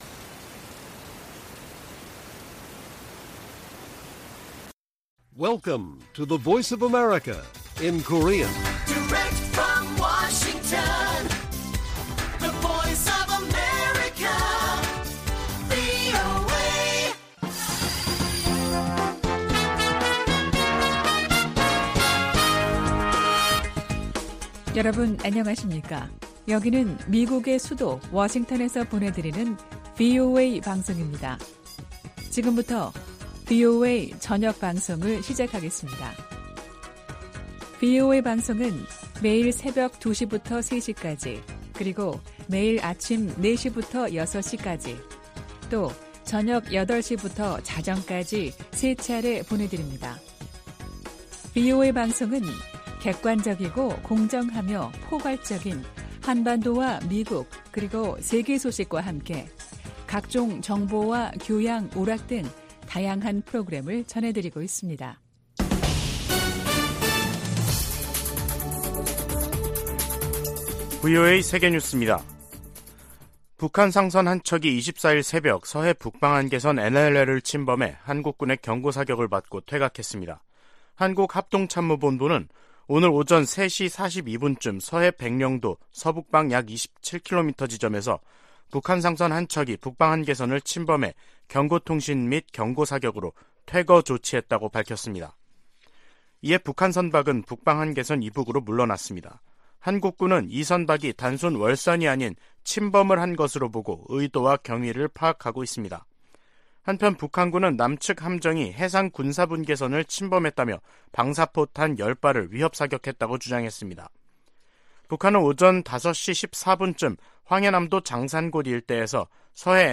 VOA 한국어 간판 뉴스 프로그램 '뉴스 투데이', 2022년 10월 24일 1부 방송입니다. 한국 합동참모본부는 24일 서해 백령도 서북방에서 북한 상선이 북방한계선(NLL)을 침범해 경고 통신과 경고사격으로 퇴거 조치했다고 밝혔습니다. 북대서양조약기구는 한국과 함께 사이버 방어와 비확산 등 공통의 안보 도전에 대응하기 위해 관계를 강화하는데 전념하고 있다고 밝혔습니다. 국제자금세탁방지기구가 북한을 11년째 대응조치를 요하는 ‘고위험 국가’에 포함했습니다.